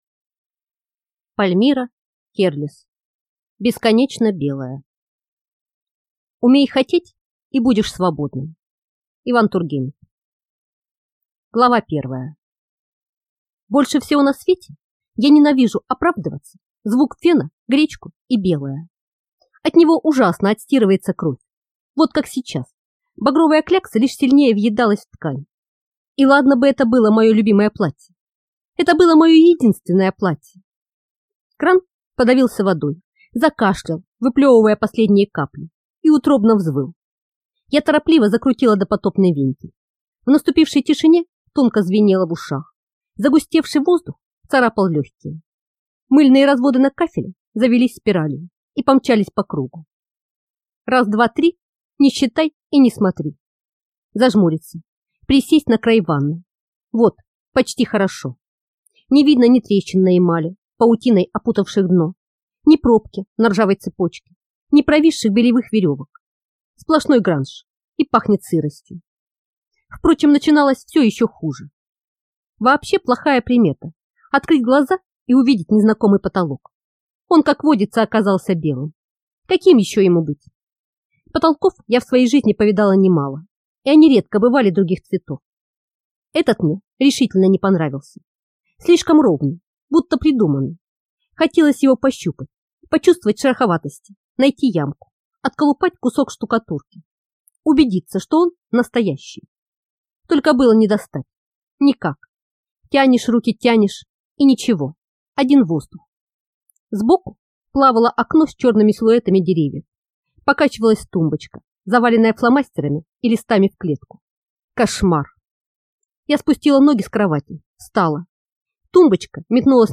Аудиокнига Бесконечно белое - купить, скачать и слушать онлайн | КнигоПоиск